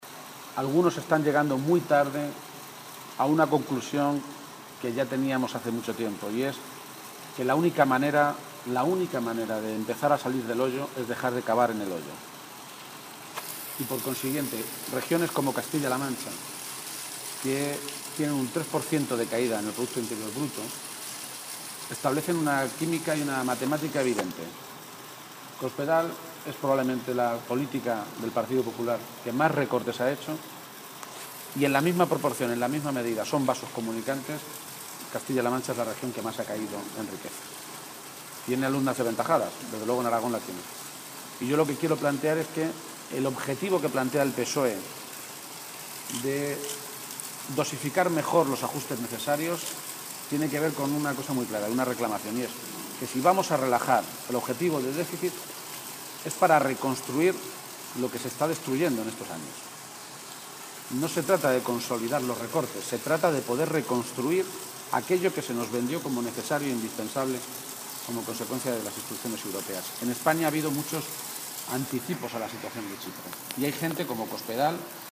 García-Page se ha pronunciado así en declaraciones a los medios de comunicación, en las Cortes de Aragón, antes de reunirse con el secretario general del PSOE aragonés, Javier Lambán, donde ha manifestado que el debate sobre la financiación autonómica «se empieza a enmarañar».
Cortes de audio de la rueda de prensa